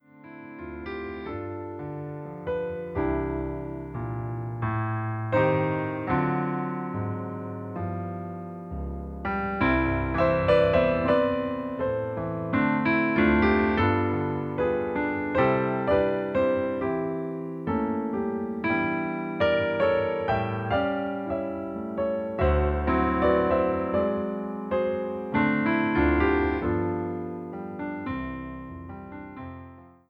Song Titles